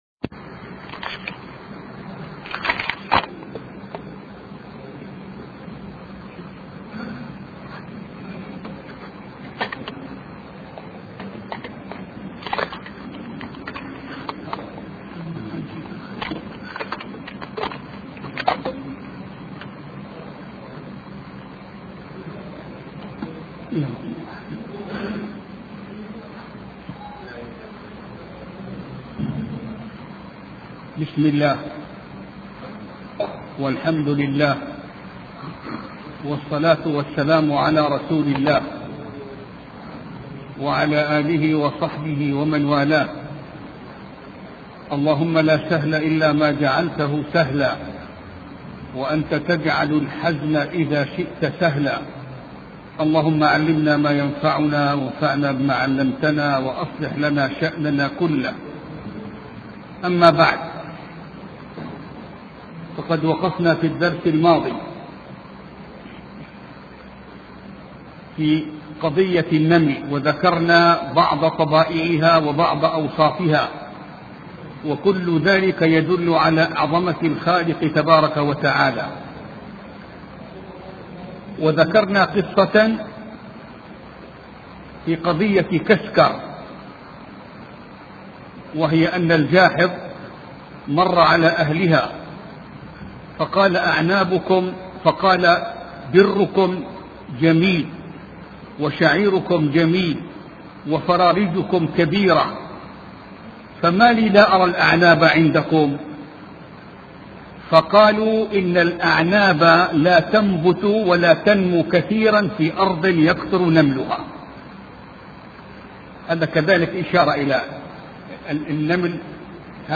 سلسلة محاضرات في قصة سليمان علية السلام